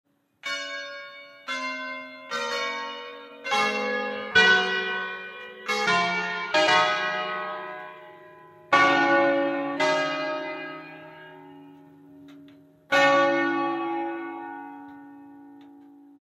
campane e campanine